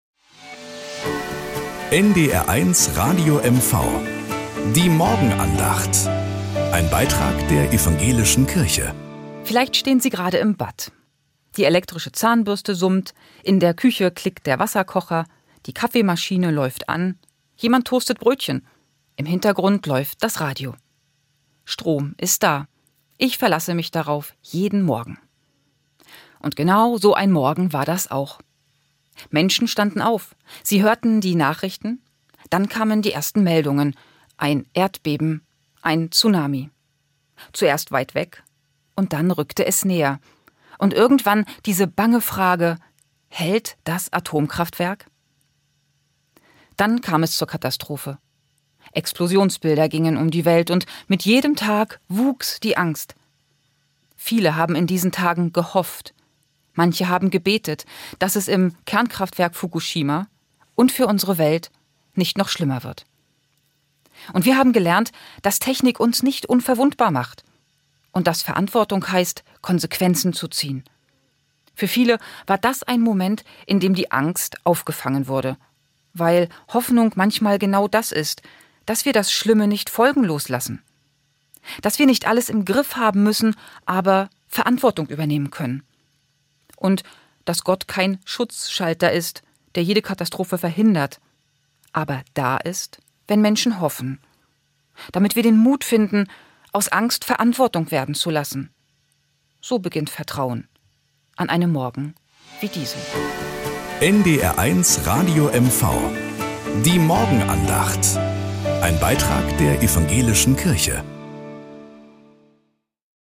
Morgenandacht bei NDR 1 Radio MV
Um 6:20 Uhr gibt es in der Sendung "Der Frühstücksclub" eine